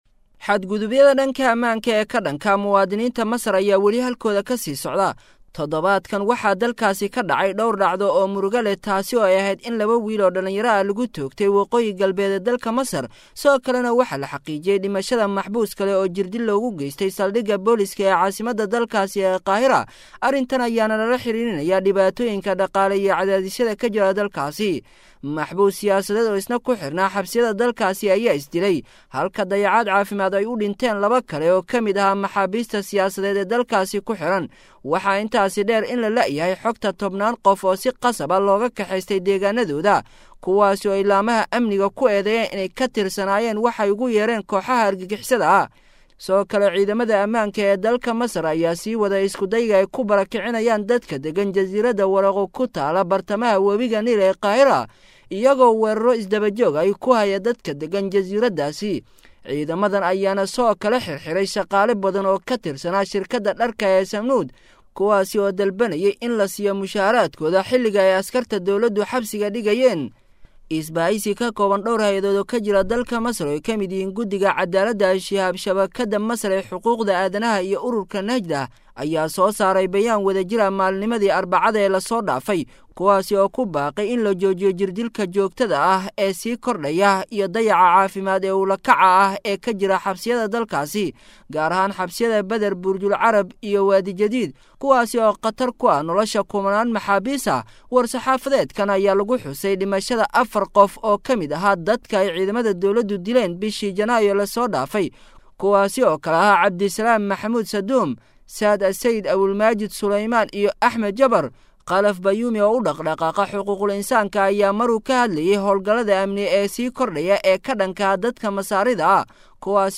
Maxaa ka Dambeeya in Ciidanka Aamniga Masar ay Xiraan Dadka Rayidka ah?[WARBIXIN] - Warbaahinta Al-Furqaan